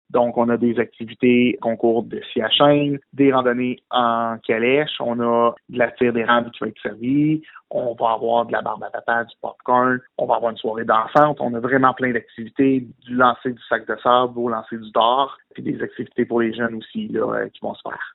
Le maire de Cayamant, Nicolas Malette, nous parle des activités qui seront disponibles ce jour-là :